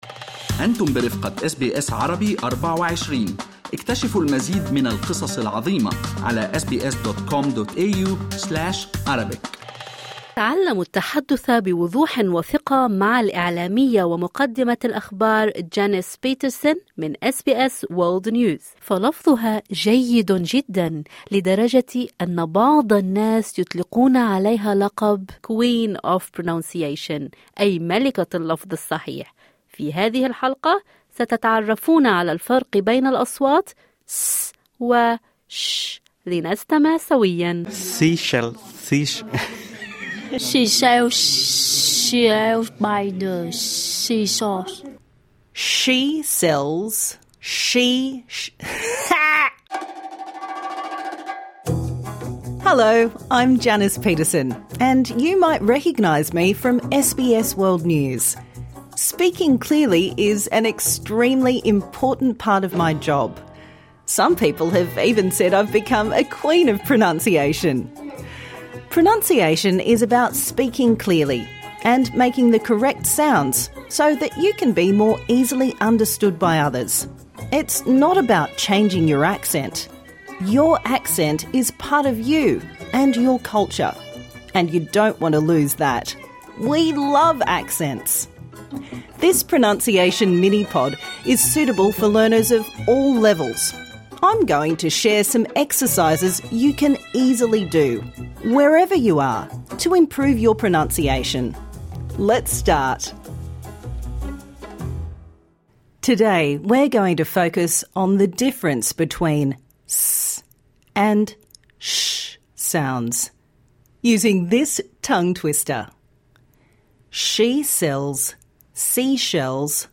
S vs SH - Pronunciation